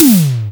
prcTTE44008tom.wav